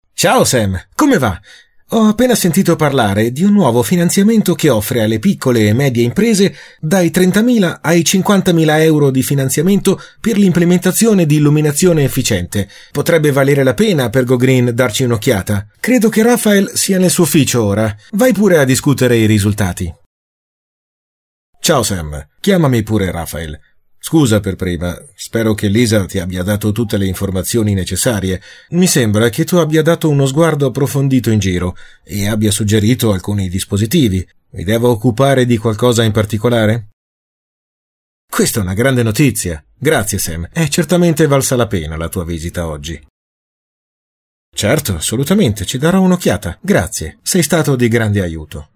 Sprechprobe: eLearning (Muttersprache):
Young and fresh voice and extremely flexible, perfect for e-learning, documentaries, web video, radio commercials, telephone responders, business, video games, etc.